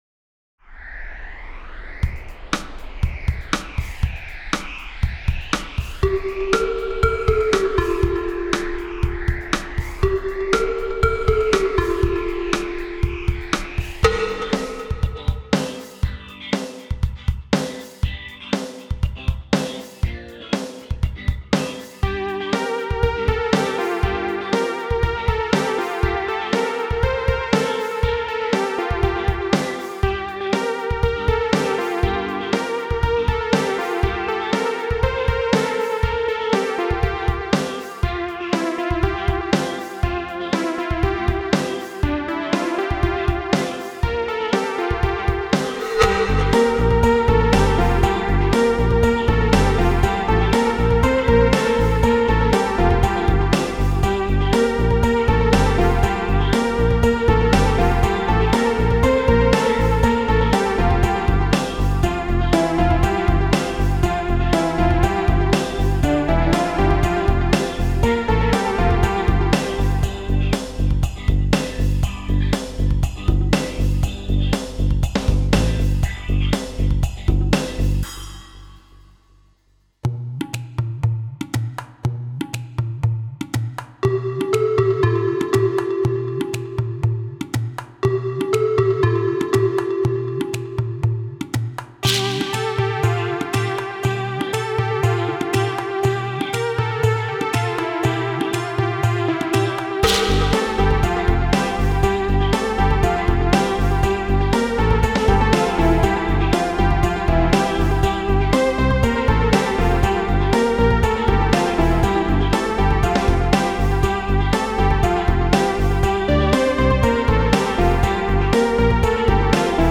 موسیقی بی کلام الکترونیک